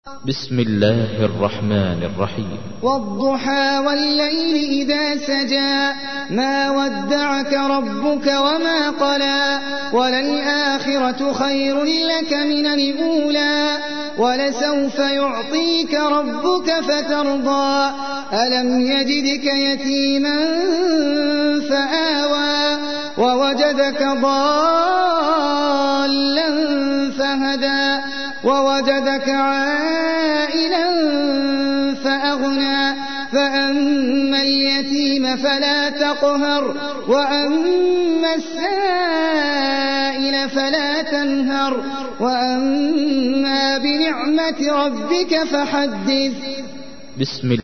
تحميل : 93. سورة الضحى / القارئ احمد العجمي / القرآن الكريم / موقع يا حسين